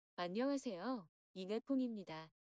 단순 소리 크기의 비교를 위한 오디오 입니다.
기준(0dB)
dB-비교-음원_기준.mp3